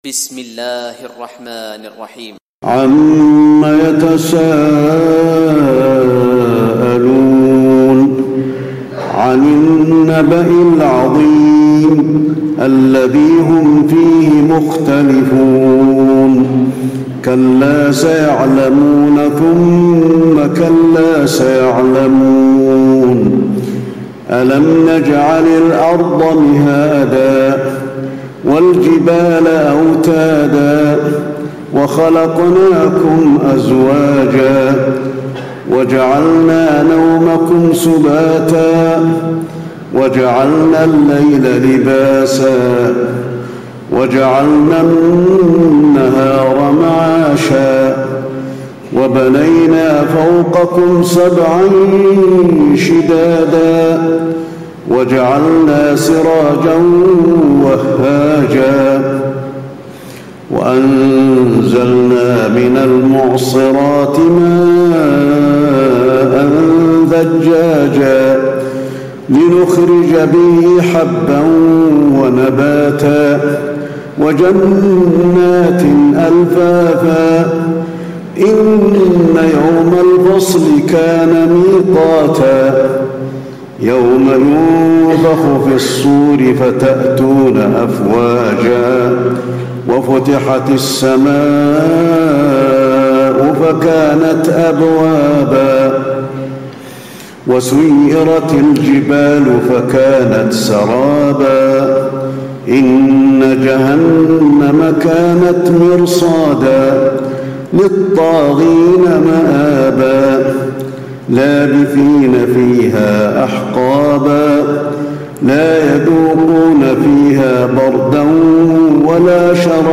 تراويح ليلة 29 رمضان 1436هـ من سورة النبأ الى التكوير Taraweeh 29 st night Ramadan 1436H from Surah An-Naba to At-Takwir > تراويح الحرم النبوي عام 1436 🕌 > التراويح - تلاوات الحرمين